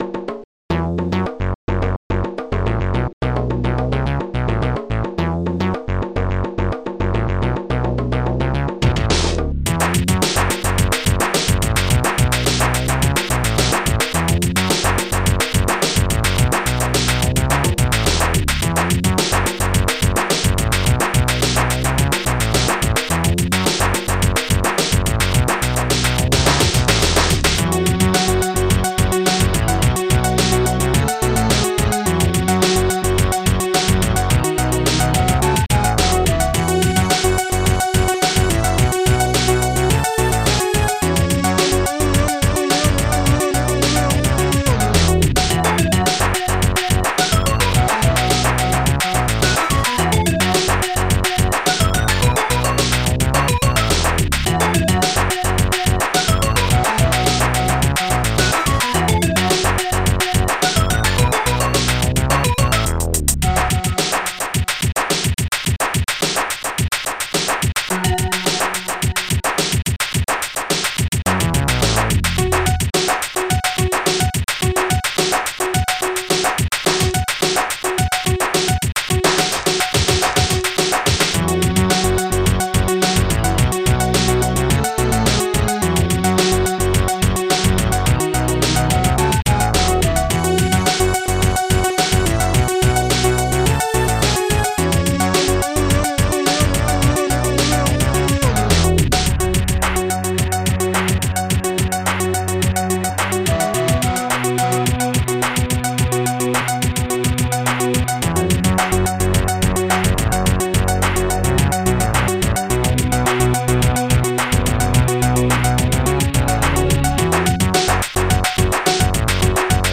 Instruments bsnare bongo moog2 clap-e badyoungbbd 19hat pling luftstring floette goodpling